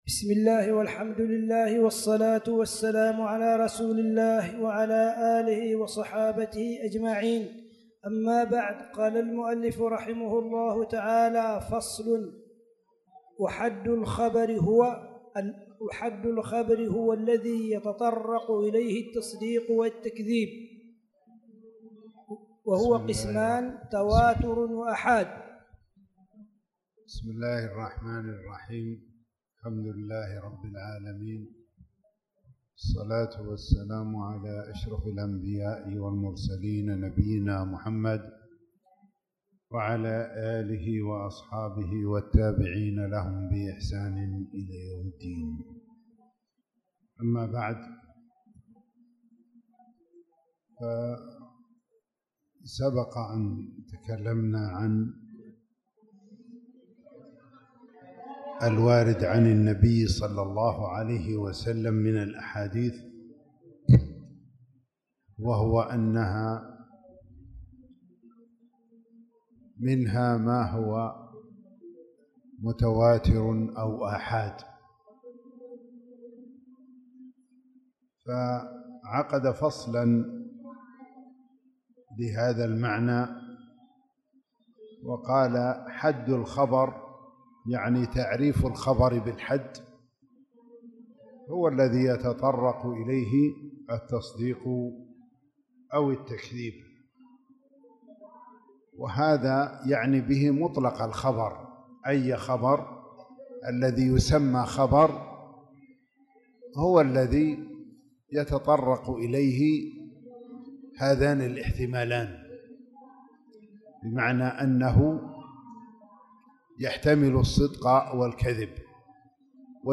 تاريخ النشر ٢٤ شعبان ١٤٣٧ هـ المكان: المسجد الحرام الشيخ